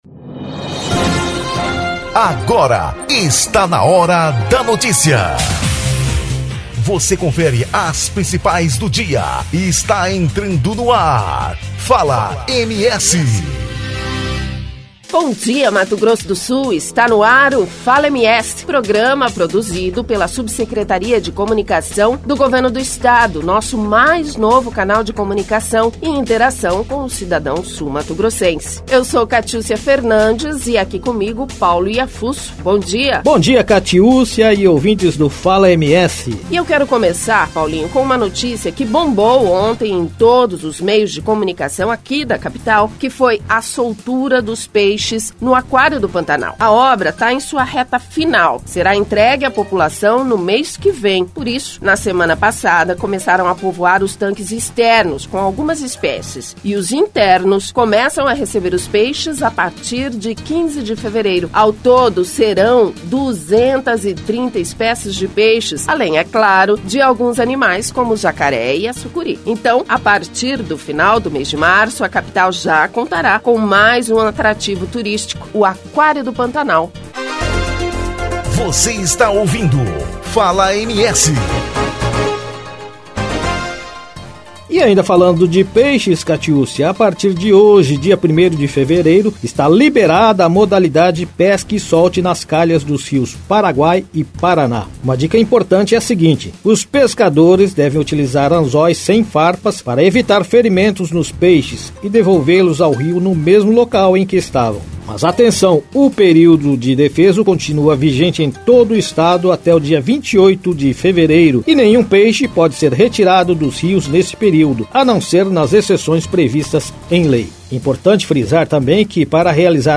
Um informativo diário e dinâmico, com notícias de serviço, saúde, segurança pública, habitação, infraestrutura, agronegócio, indústria, comércio, enfim tudo que diz respeito ao trabalho realizado pelo Governo do Estado para o cidadão sul-mato-grossense.